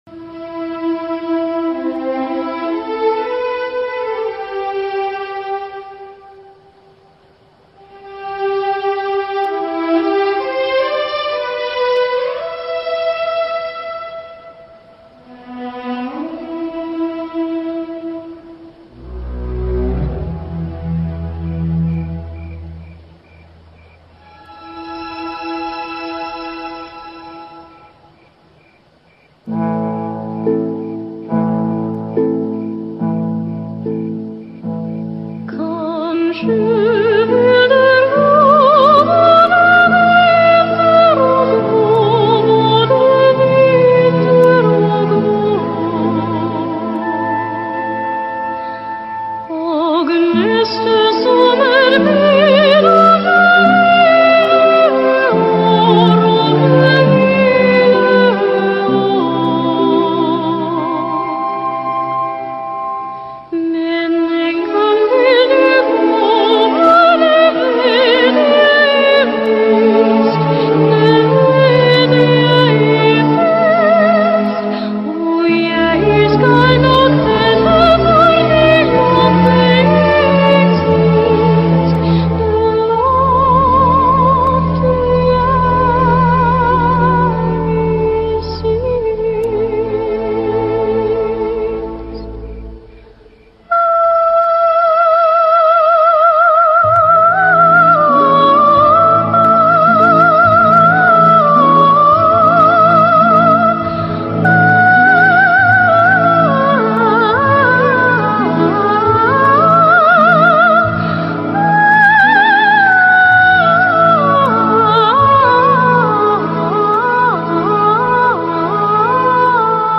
[28/5/2010]金属般质感美声-- Mirusia Louwerse 激动社区，陪你一起慢慢变老！